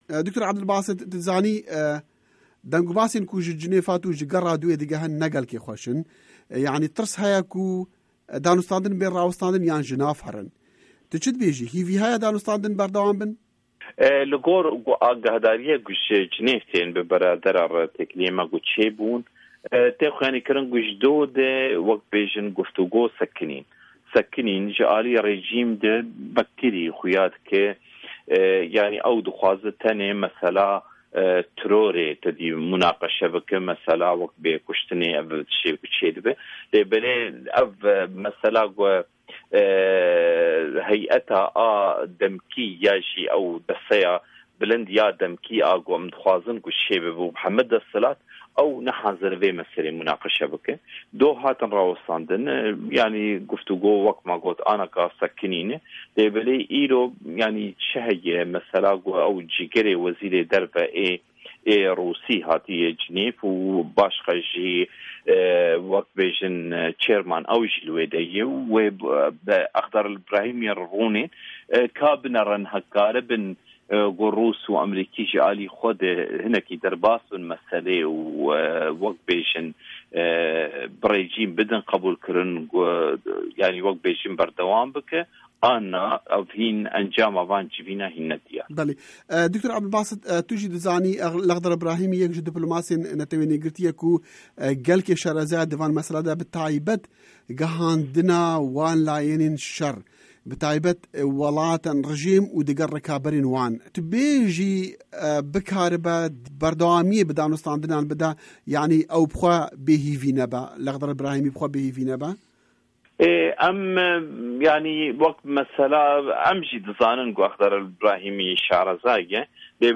Hevpeyivin digel Dr. Ebdulbasit Seyda